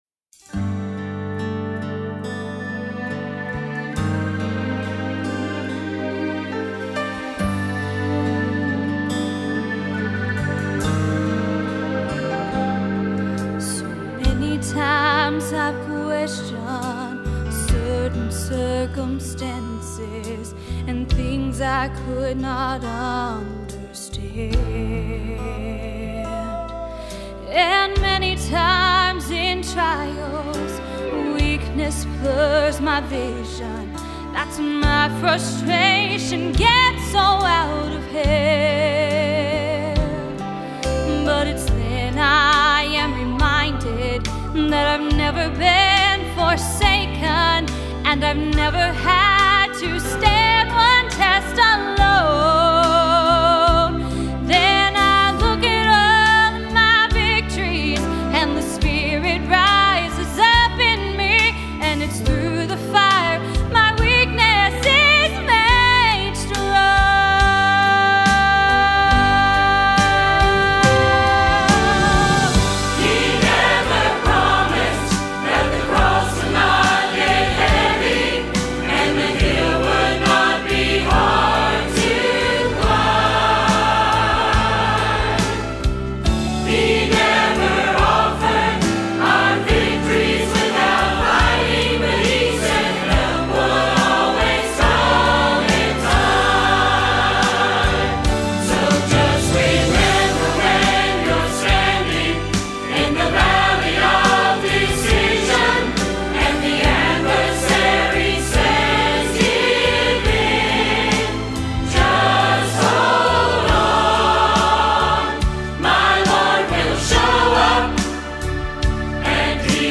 spiritual gospel singing